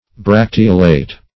Search Result for " bracteolate" : Wordnet 3.0 ADJECTIVE (1) 1. having bracteoles ; The Collaborative International Dictionary of English v.0.48: Bracteolate \Brac"te*o*late\, a. (Bot.)